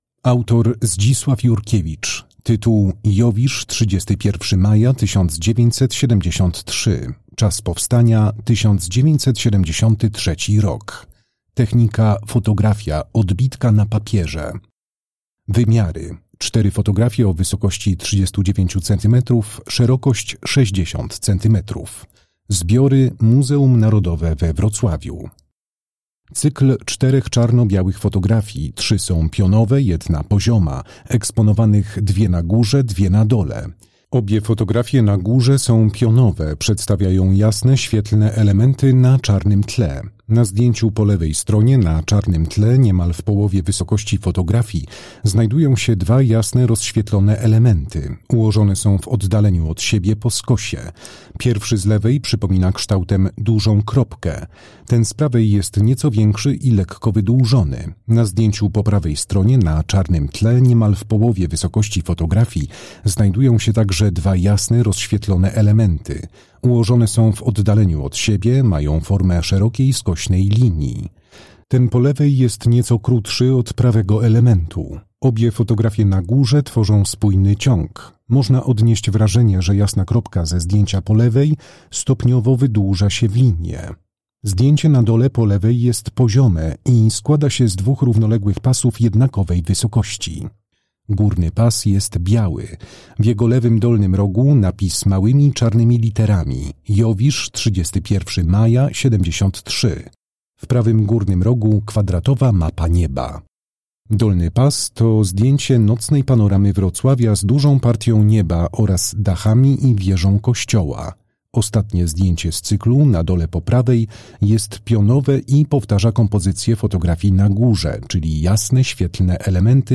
Audiodeskrypcje